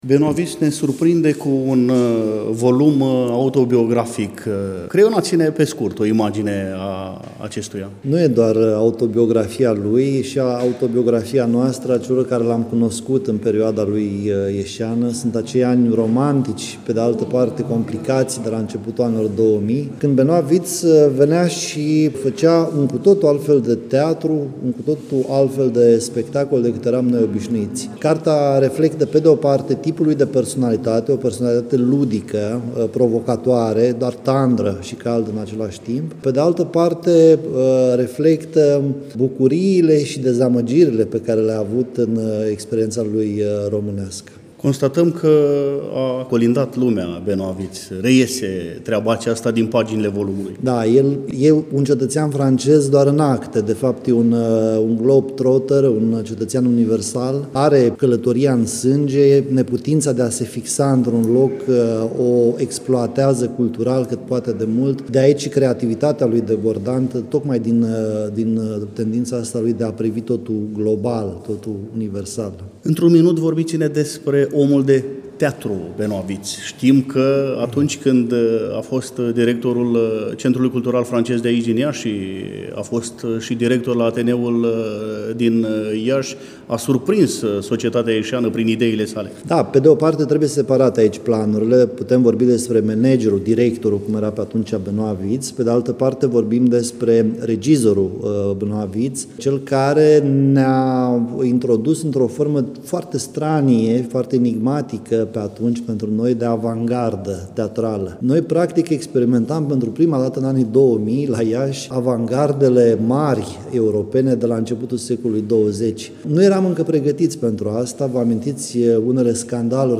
eveniment care s-a desfășurat, la Iași, nu demult, în incinta Palatului Braunstein